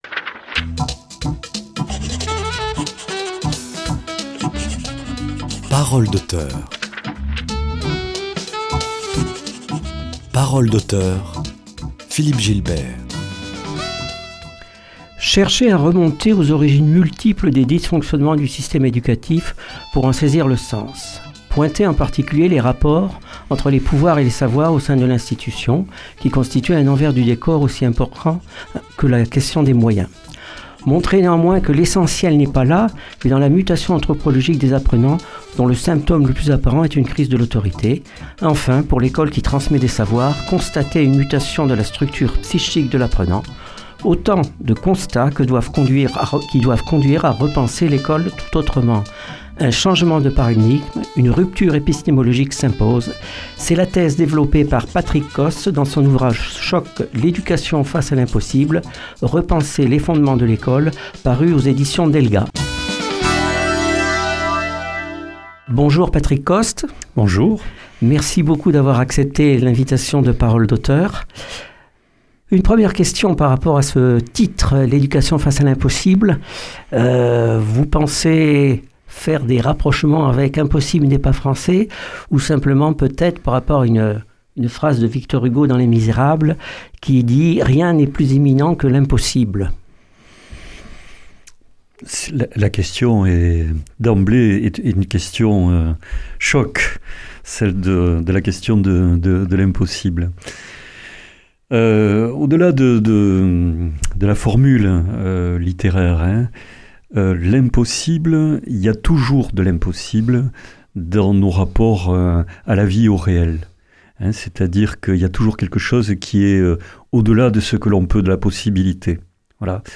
Accueil \ Emissions \ Culture \ Littérature \ Paroles d’Auteur \ L’éducation face à l’impossible